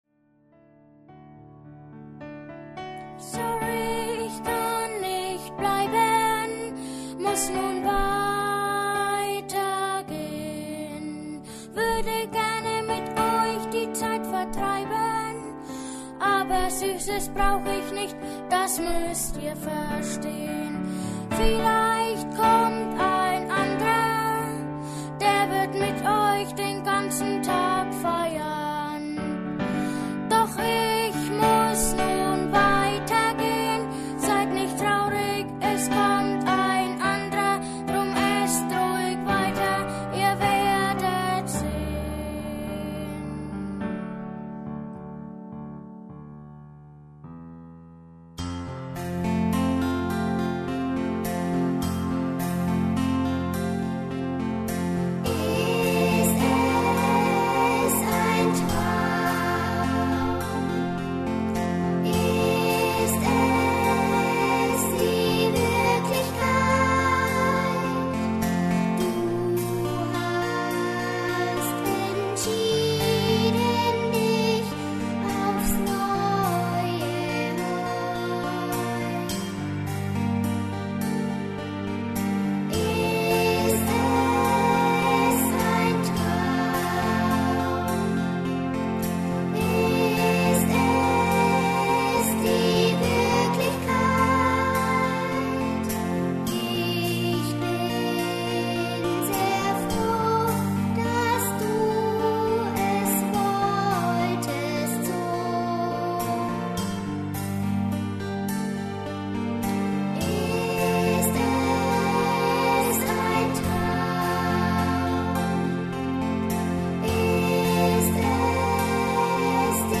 Musical für Kinder- und Jugendchor
Instrumentalbegleitung: Klavier und Streichinstrumente Uraufführung 2001 bzw 2013 durch den Kinder- und Jugendchor Young Harmony
Kinderchor einstimmig, Jugendchor (anspruchsvoller Part, mit Chorsoloparts) Solisten / Darsteller aus Kinder- und Jugendchor